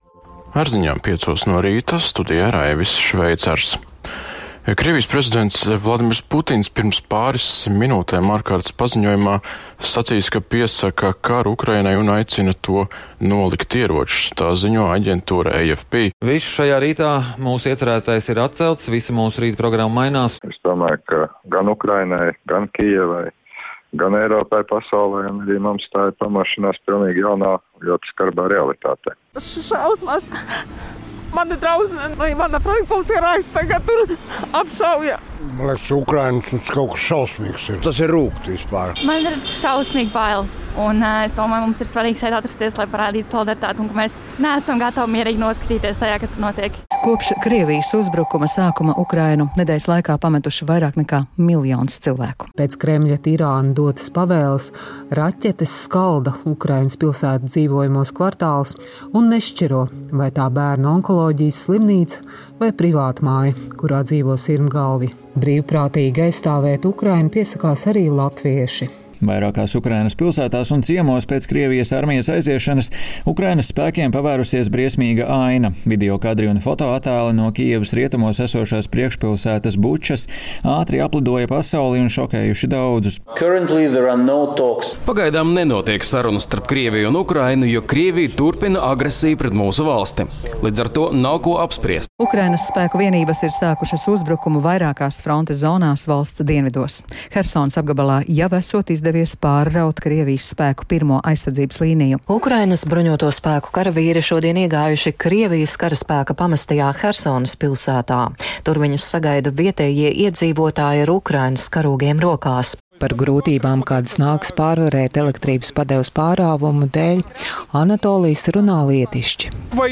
Bez liekiem komentāriem turpmākās dažas minūtes atskats trīs gadus ilgajā kara hronikā, kas izskanējusi Latvijas Radio ēterā, atgādinot, kas uz kārts bija toreiz – un kas ir tagad.